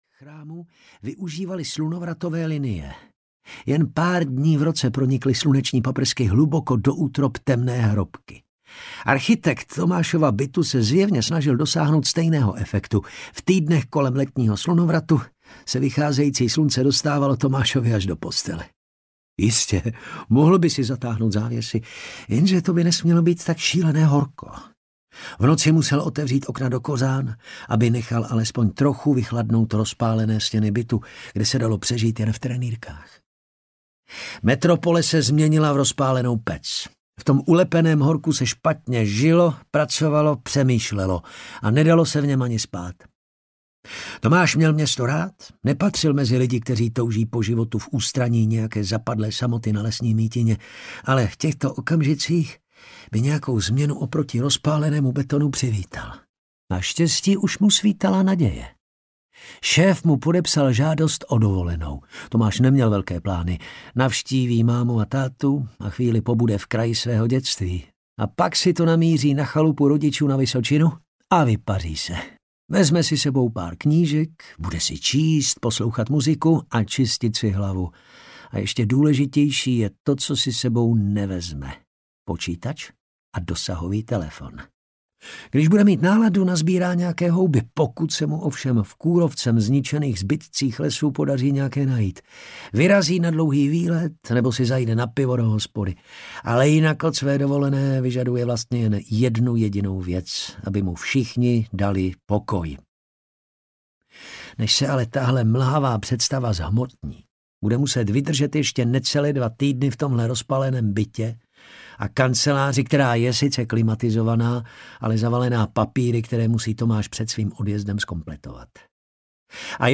Nalezení audiokniha
Ukázka z knihy